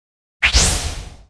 快速腾空zth070509.wav
通用动作/01人物/02普通动作类/快速腾空zth070509.wav
• 声道 單聲道 (1ch)